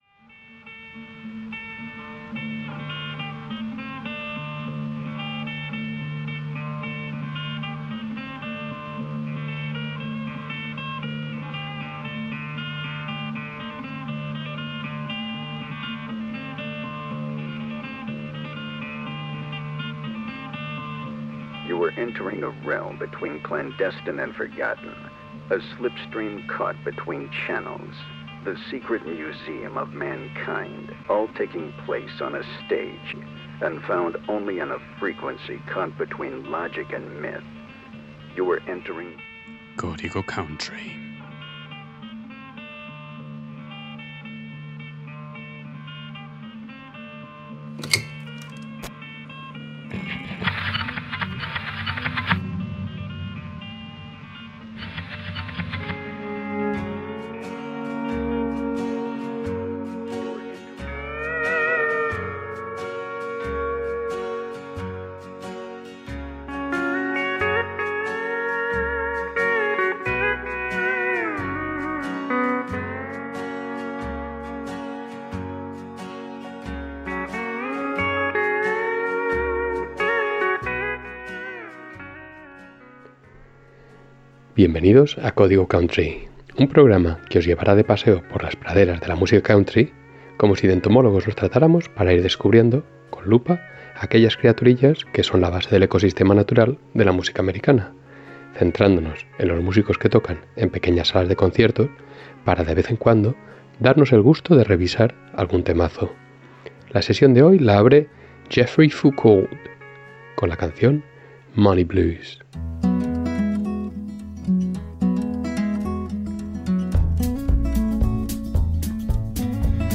Hoy en Código Country, os presentamos una mezcla de canciones que nos llevan del presente al pasado. Preparados para un viaje musical que explorará, algunas canciones que hablan de la codeína.